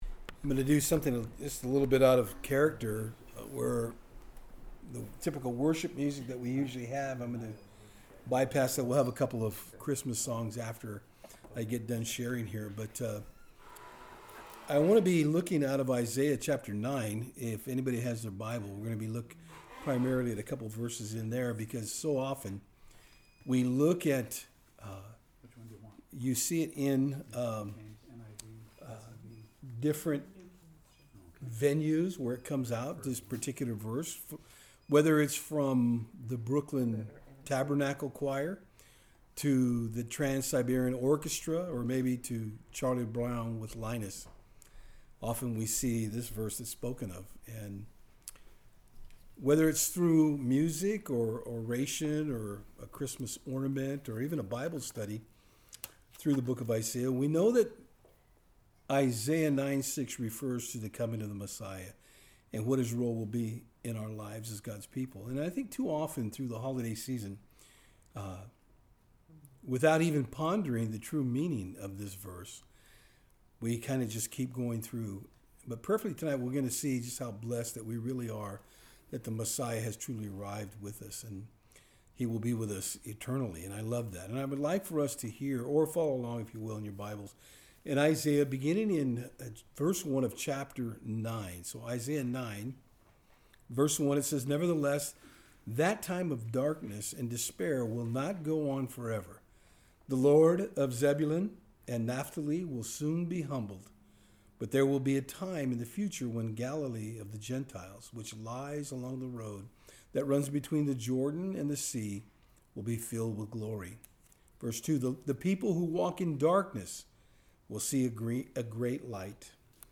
Christmas Message
Saturdays on Fort Hill